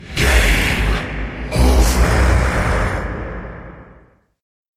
failsound.mp3